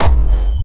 polygon_explosion_blackhole.wav